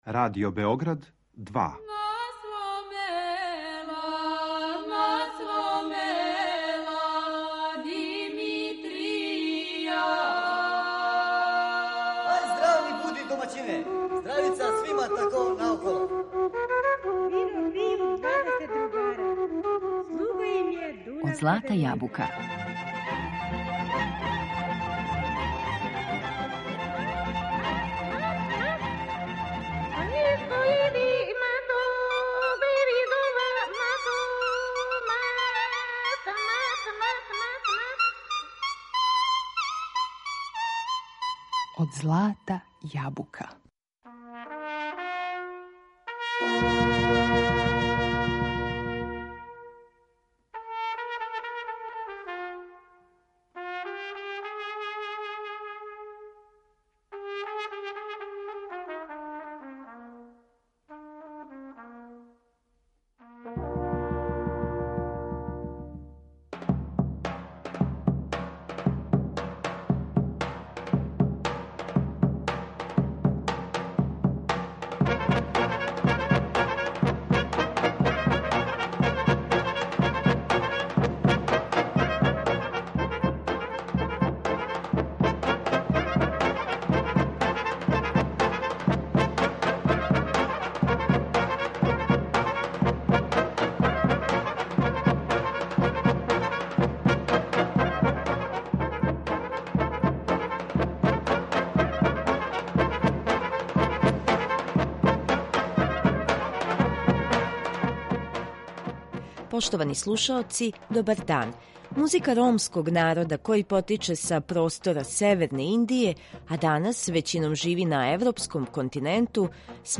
Ромска музика Балкана
Ромска музика је увек обележена националним или регионалним карактеристикама простора који насељавају, па ипак, има нешто што заједнички израз чини посебним и препознатљивим. Данашње издање емисије Од злата јабука посвећено је управо балканској ромској музици.